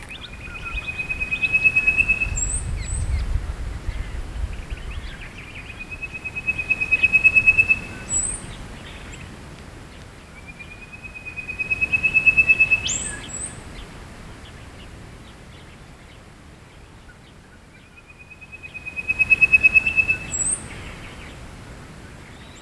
Copperback Quail-thrush
Yumbarra Conservation Park, Yumbarra, South Australia, Australia
Cinclosoma clarum fordianum
Quail-thrush Copperback (fordianum) YUMBARRA SA AUS call & contact [B] ETSJ_LS_71204.mp3